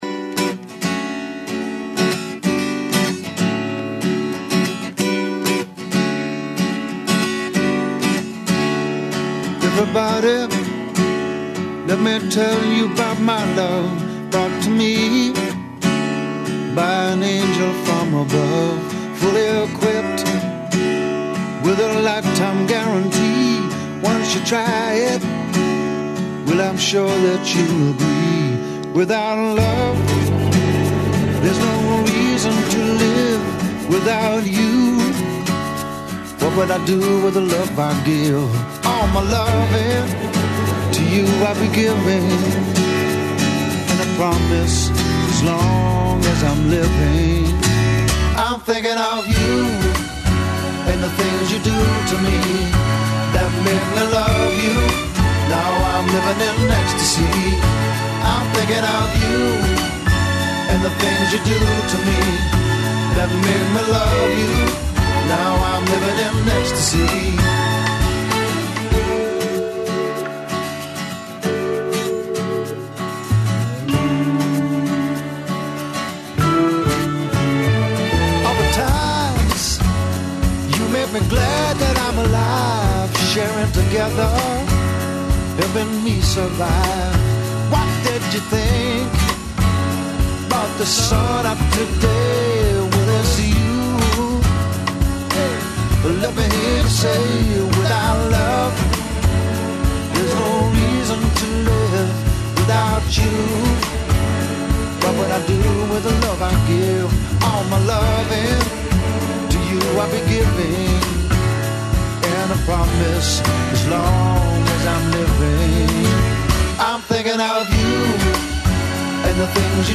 Међународни фестивал 'Ринг Ринг', 17. по реду траје до 21. маја на шест локација у граду, уз учешће музичара из 15 земаља. Најавићемо и Ноћ музеја, а у студију ће уживо наступати вокална група 'Кода'.